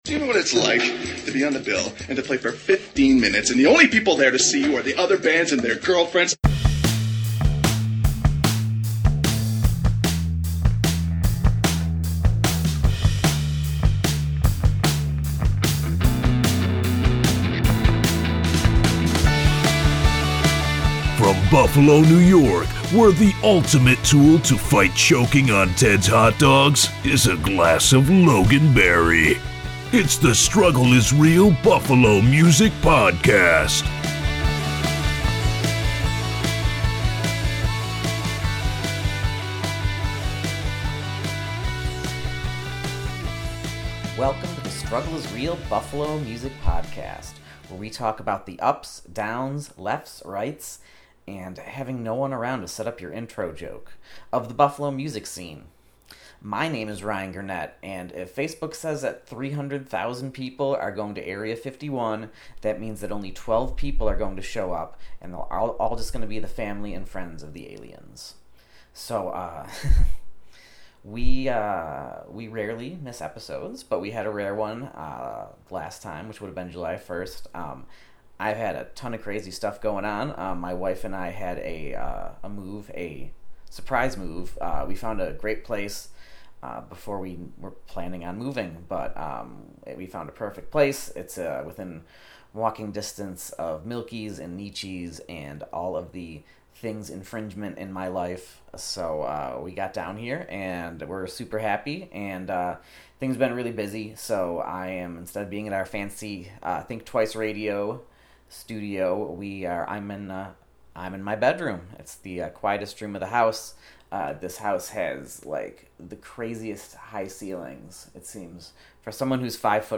we play music
as well as exclusive live tracks from The Scarecrow Show and THIS.